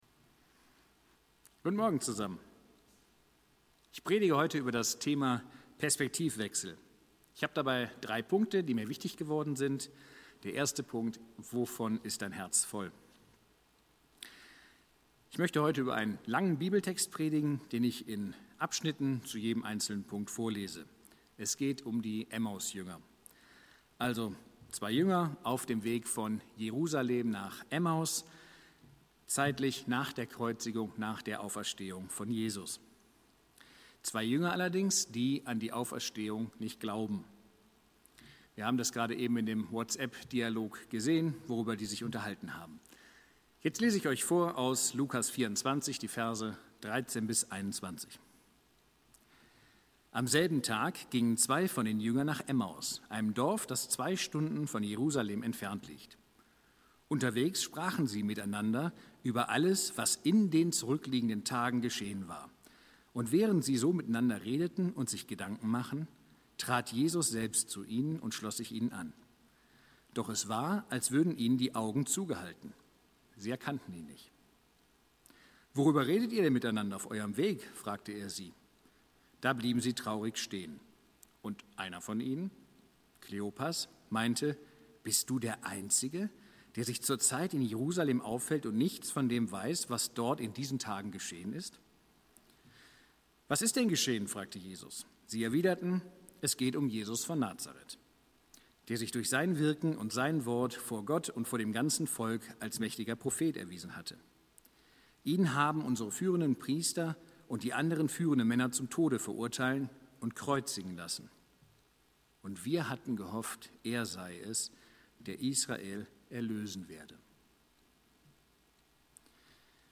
Predigt-03.05.mp3